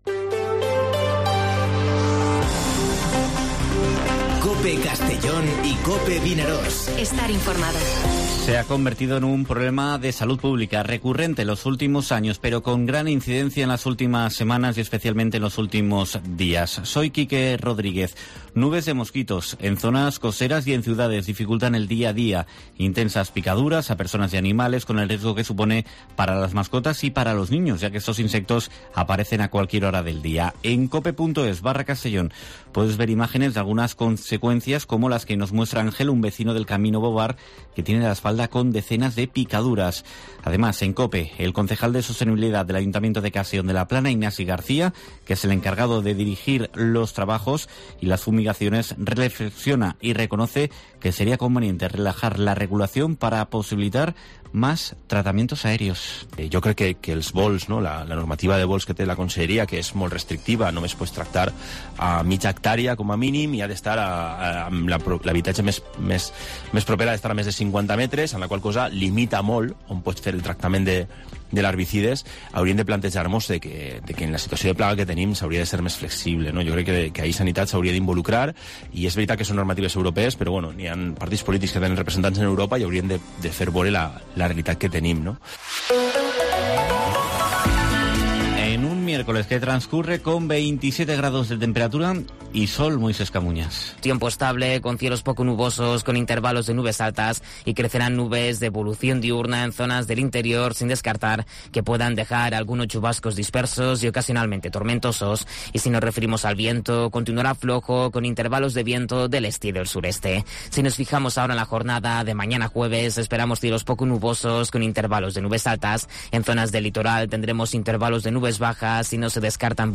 Informativo Mediodía COPE en Castellón (18/05/2022)